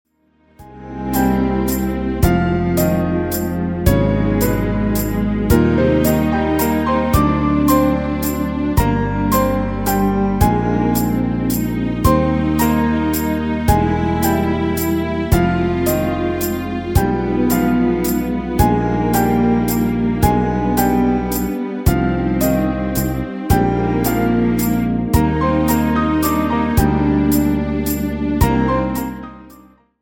AMBIENT MUSIC  (2.28)